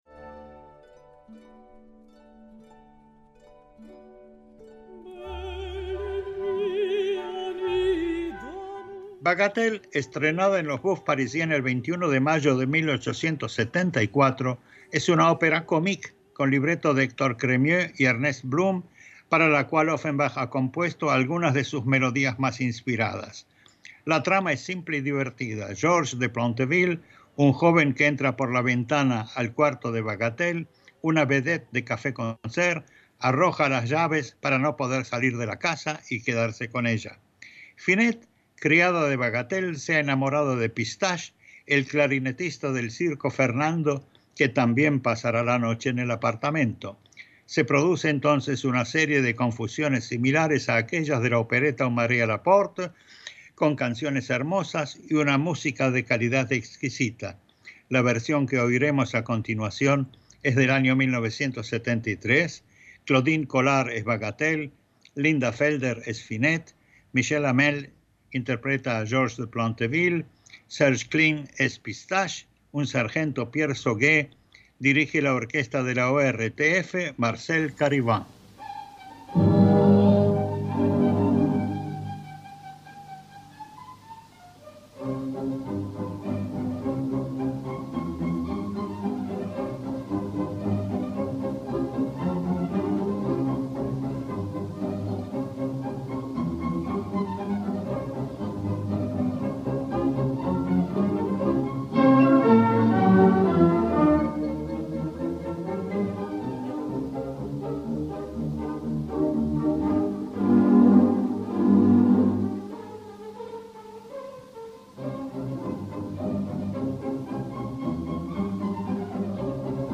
Se produce entonces una serie de confusiones similares a aquellas de la opereta Un mari à la porte, con canciones hermosas y una música de calidad exquisita.